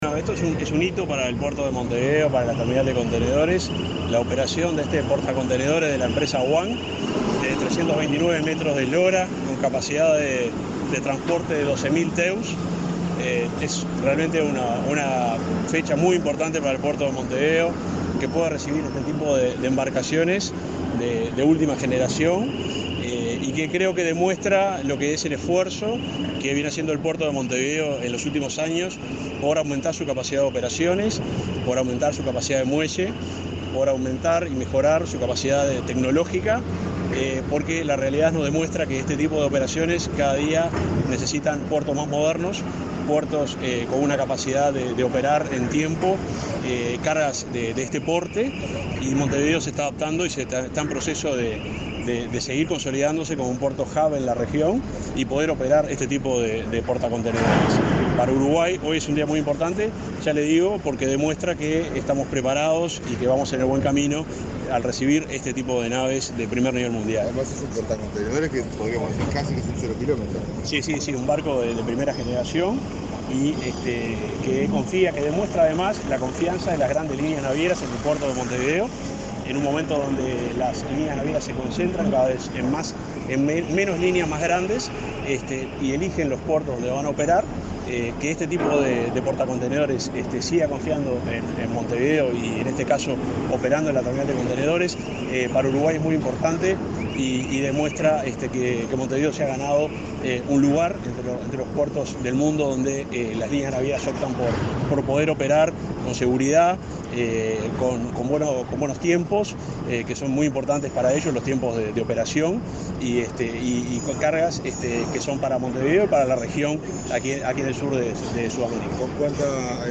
Declaraciones del subsecretario de Transporte
El subsecretario de Transporte, Juan José Olaizola, participó en el puerto de Montevideo en un acto por el arribo a Uruguay del buque